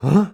Male_Grunt_Curious_03.wav